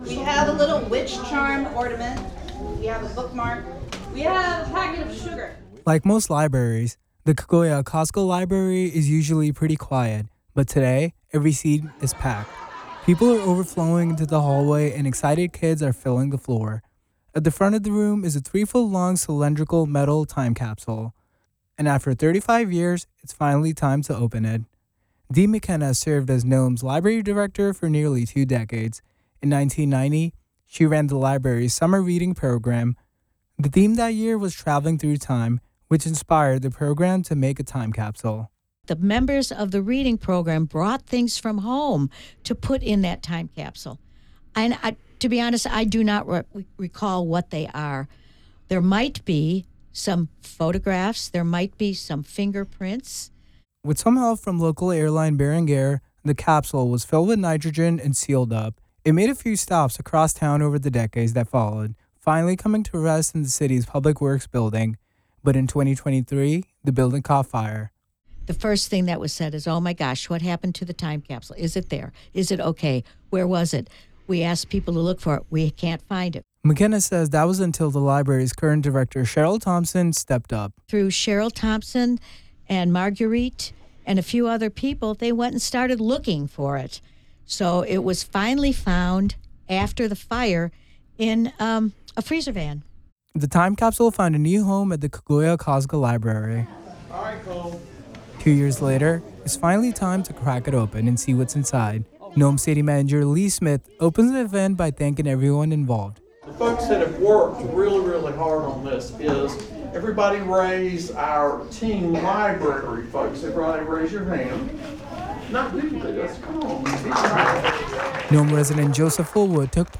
The Richard Foster room next door to the library buzzed with energy as people filled nearly every seat in the room.
“Folks that have worked really really hard on this, our library folks everybody raise your hand, you can do this come on now,” he said to cheers from the crowd.